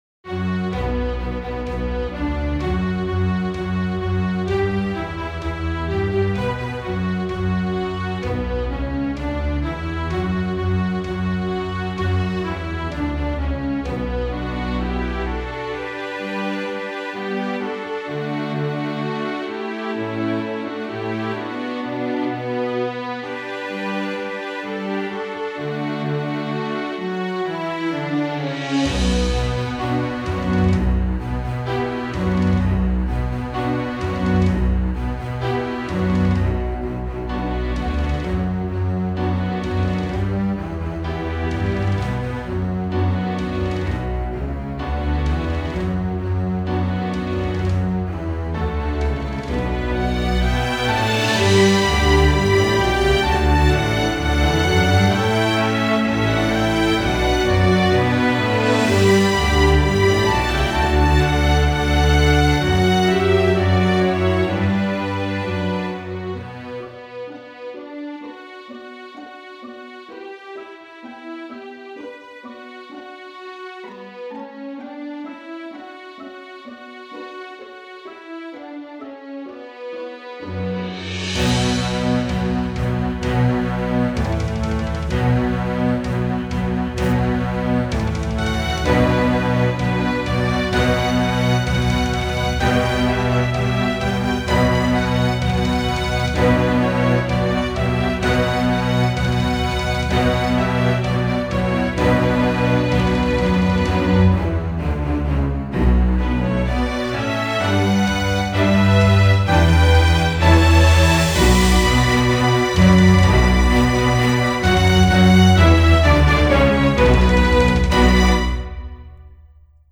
мини-увертюра